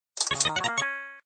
SMiniLockOpen.ogg